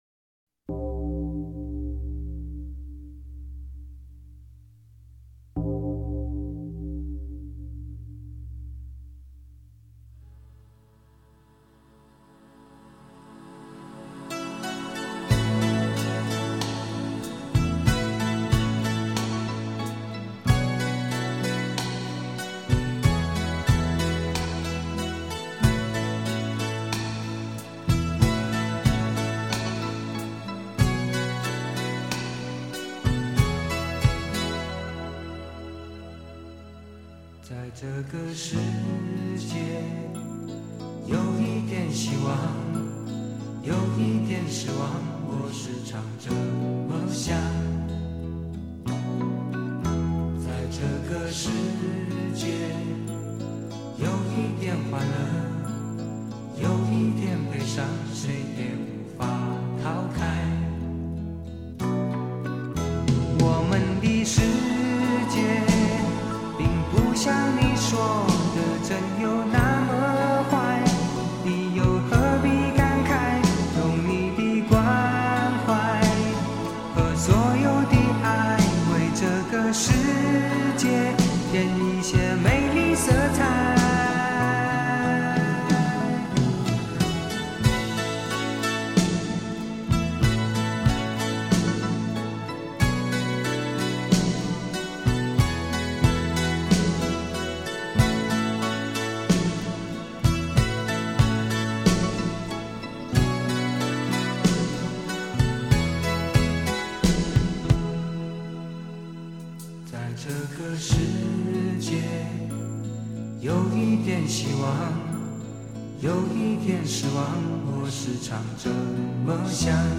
他的每一首作品都流露出一股特殊的气质，冷淡而深刻，就如同他的人。